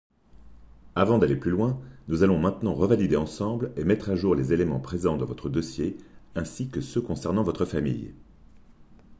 Voix conseillé financier
- Basse